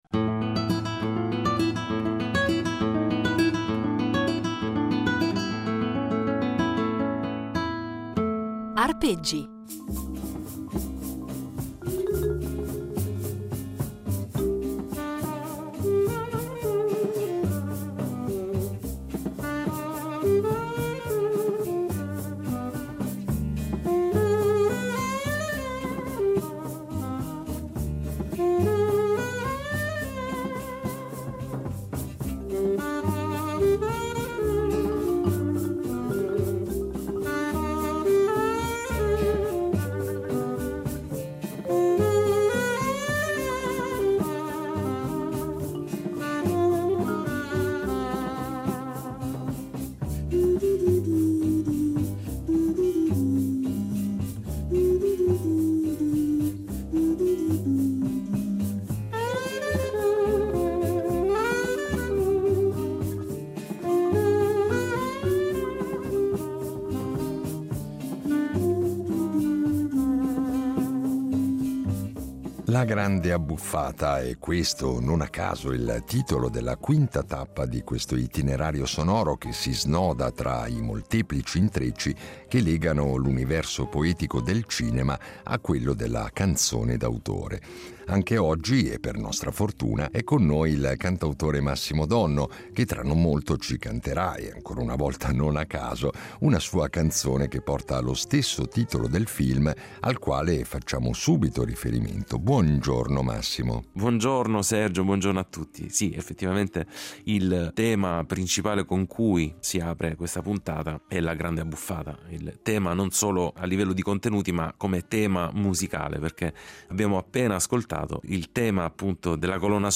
Insieme, andranno quindi ad impreziosire i nostri itinerari sonori con esecuzioni inedite di canzoni che hanno contribuito a rendere indimenticabili alcune pellicole cinematografiche.